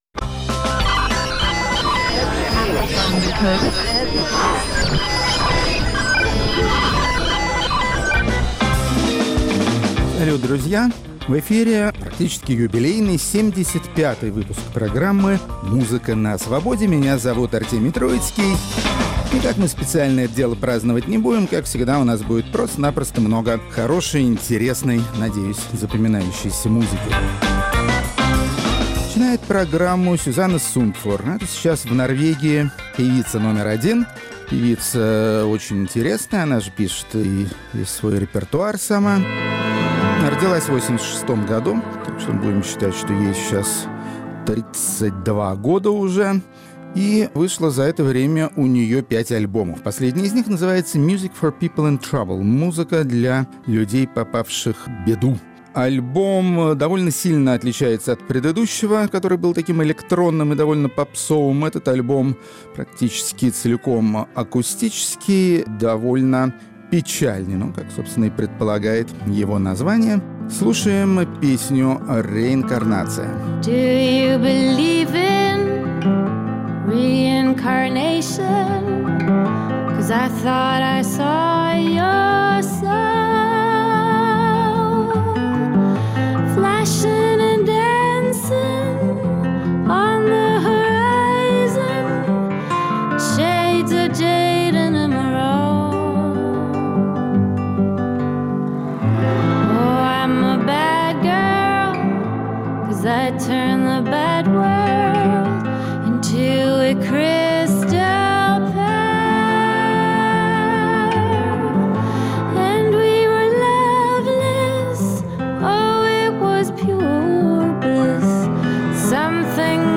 Музыка на Свободе. 30 июня, 2018 Музыканты из Латвии, выступающие в разных жанрах и работающие в разных направлениях современной популярной музыки. Рок-критик Артемий Троицкий напоминает о том, что в былые времена латвийская музыка в России была хорошо известна, и желает рокерам из Риги и Лиепаи нового европейского успеха.